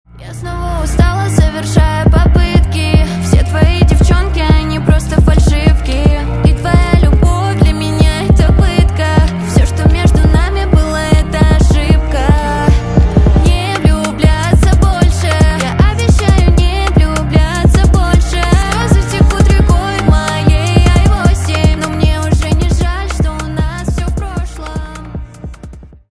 на русском грустные на бывшего